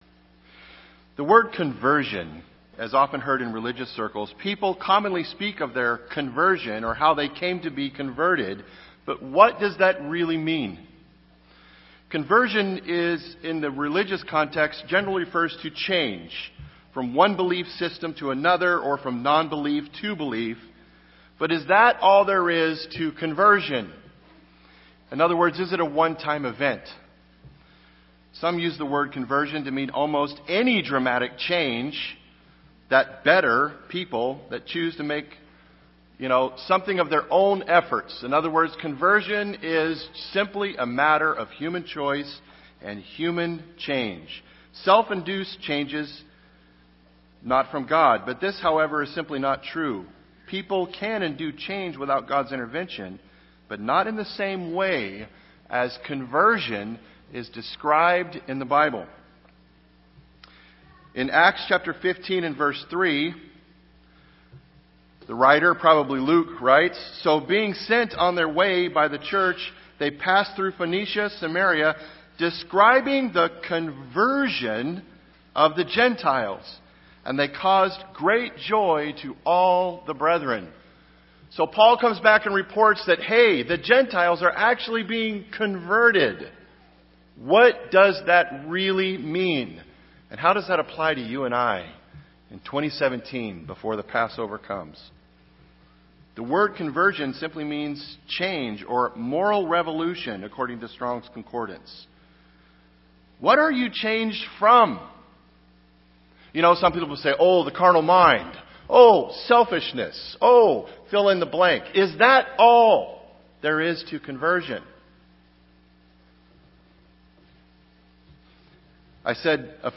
This message begins a series of sermons that will discuss and give us the true meaning of conversion as it applies to God’s people. This series will examine the Bible's teaching on conversion.